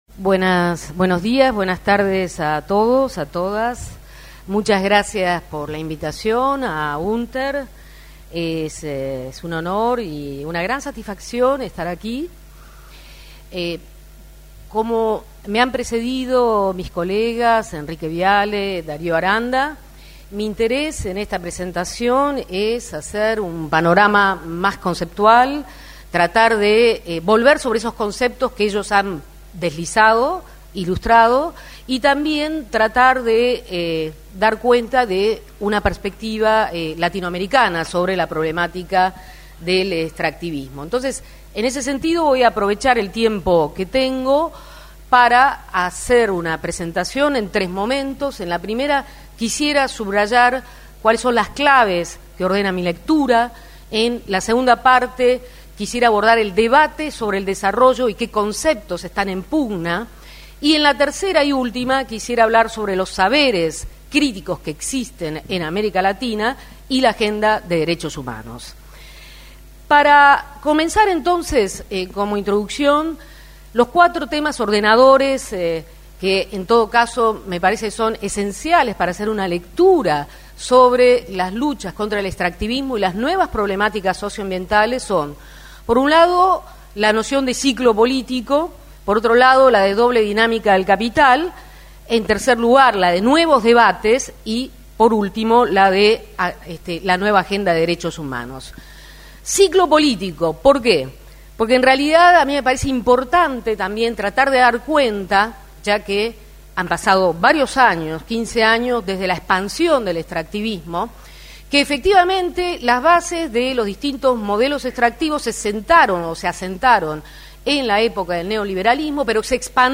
MARISTELLA SVAMPA - EXPOSICION PRIMERA PARTE.mp3